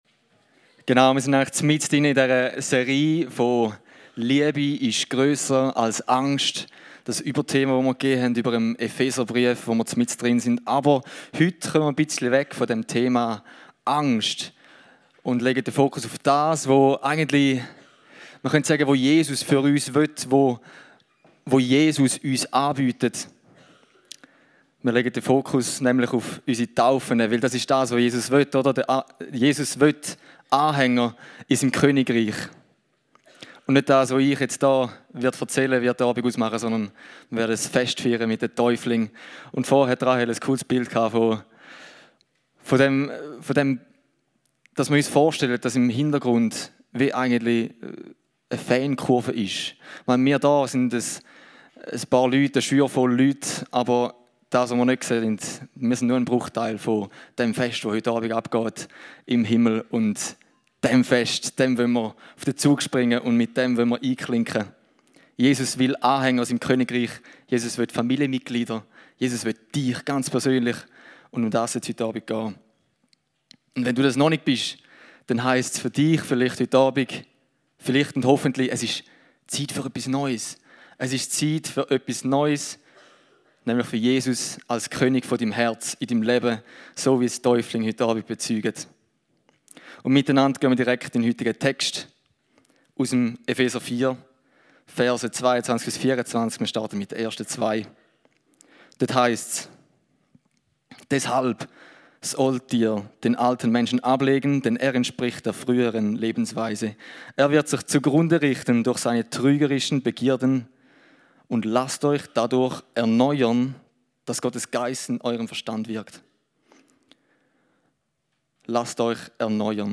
Eine predigt aus der serie "RISE & FALL." Freundschaft ist kein Bonus – sie ist lebensnotwendig. In dieser Predigt tauchen wir ein in die bewegende Geschichte von David und Jonathan und entdecken, was echte Freundschaft ausmacht: Commitment, Risiko, geistliche Tiefe und Liebe.